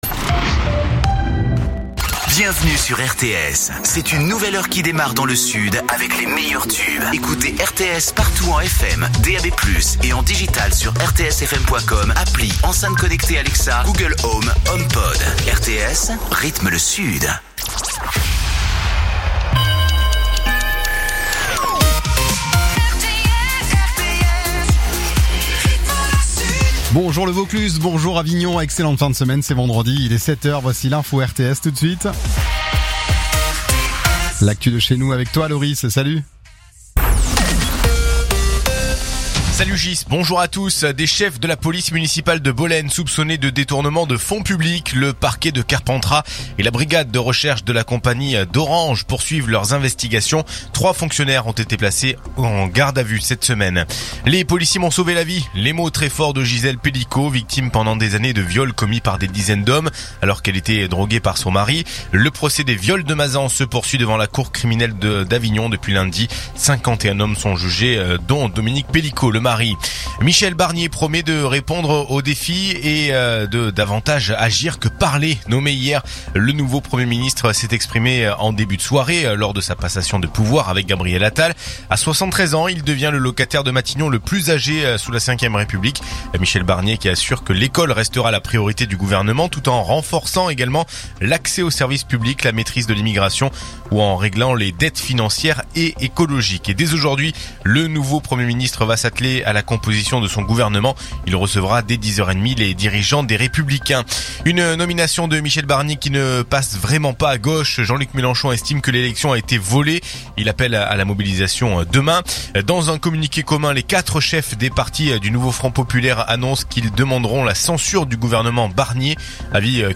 Écoutez les dernières actus d'Avignon en 3 min : faits divers, économie, politique, sport, météo. 7h,7h30,8h,8h30,9h,17h,18h,19h.